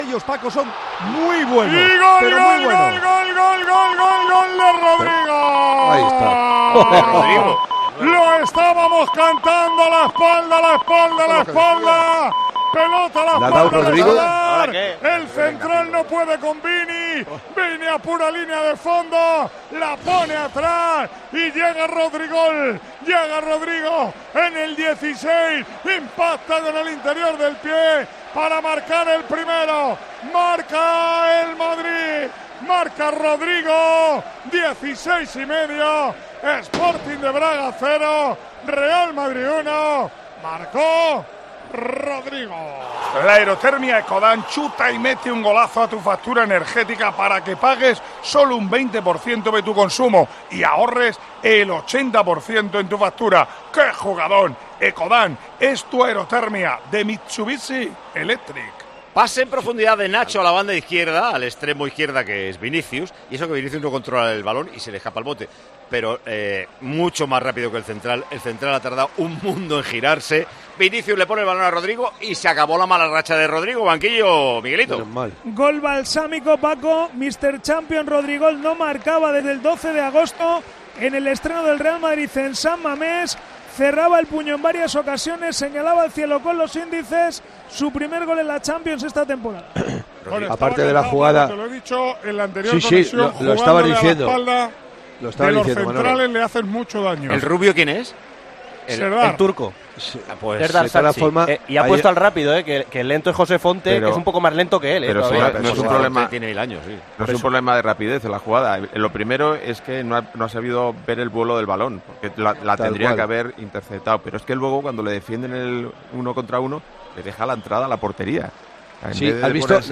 Así narró Manolo Lama los goles que llevaron al Real Madrid a conquistar la Decimoquinta